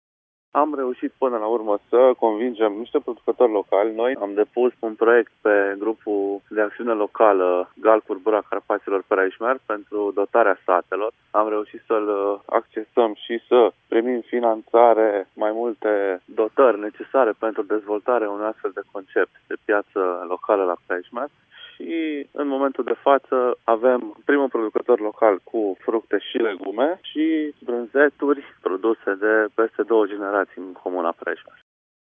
Iniţiatorul proiectului, administratorul public al comunei Prejmer, Mihai Apafi: